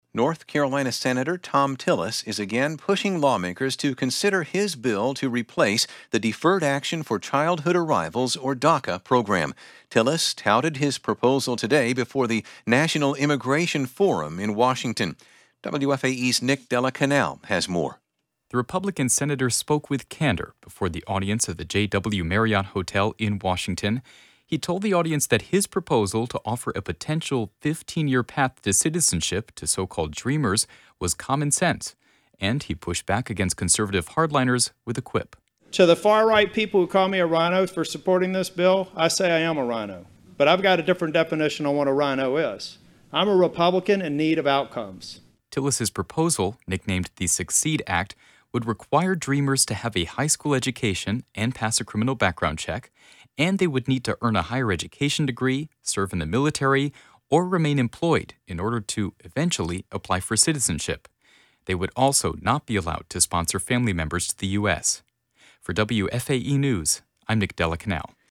The remark drew laughter and applause from the audience.